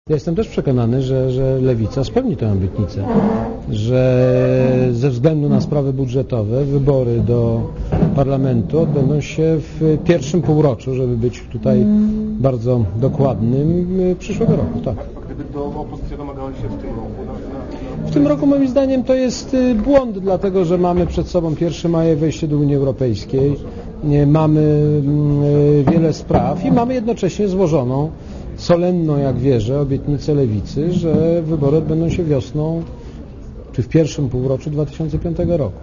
Mówi prezydent Kwaśniewski (137 KB)